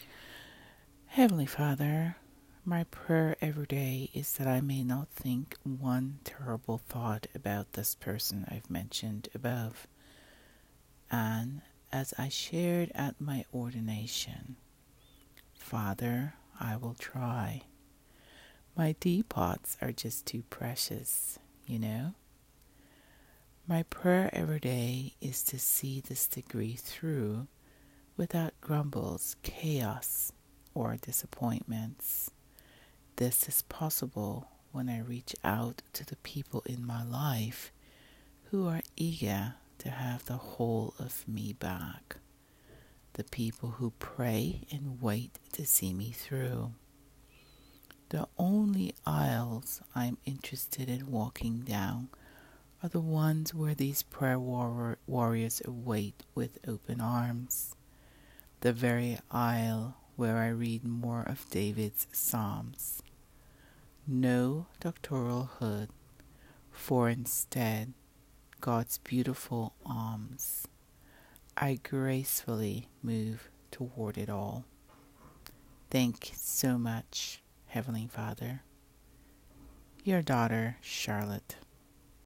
Spoken words: